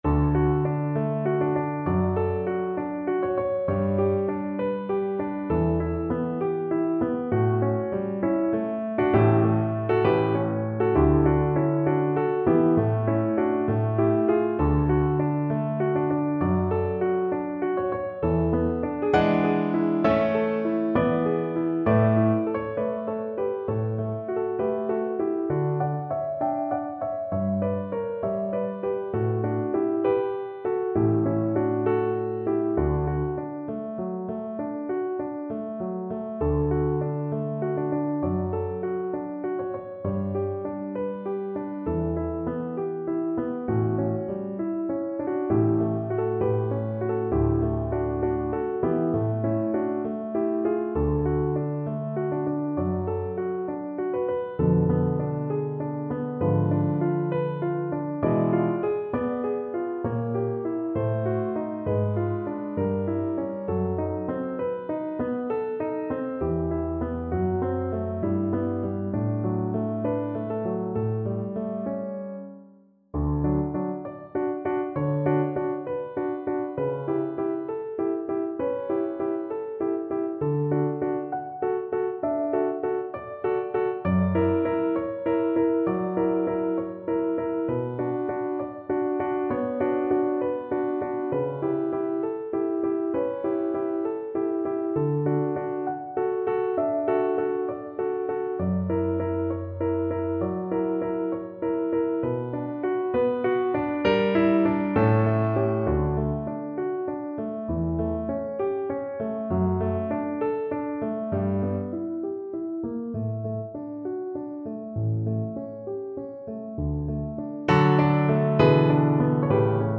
Cello
D major (Sounding Pitch) (View more D major Music for Cello )
4/4 (View more 4/4 Music)
Andantino =66 (View more music marked Andantino)
D4-G5
Classical (View more Classical Cello Music)